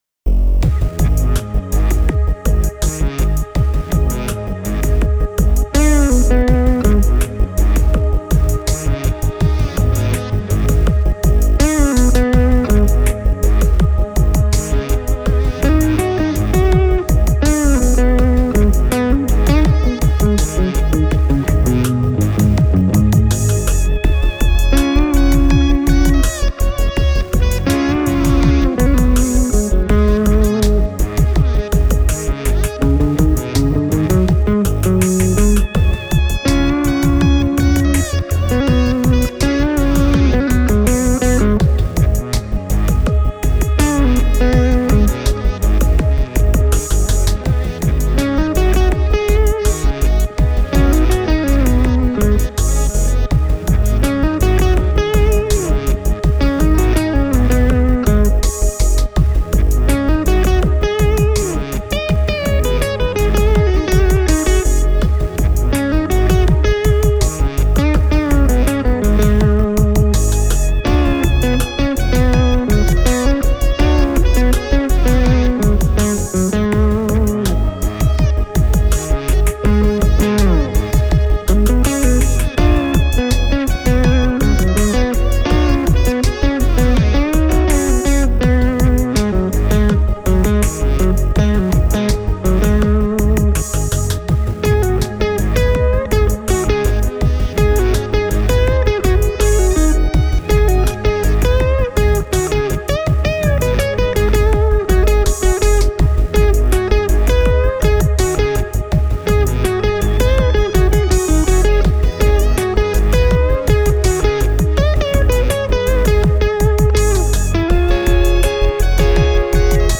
Ehdin kuitenkin äänittää kaksi, tyyliltään hyvin erilaisia demobiisiä.
Ensimmäisessä biisissä 54 Phi -kombo oli 9 Watts -moodissa, jolloin Goldfingeria sai humbuckereilla säröön vielä olohuone-volyymillä. Soolokitarana toimii Gibson Les Paul Junior (kitaran tone-potikka oli säädetty hieman alas), kun taas takaperin soivat kitaralinjat on soitettu Gibson Melody Maker SG -mallilla sisään (molemmissa biiseissä kitaramikkinä toimii Shure SM57):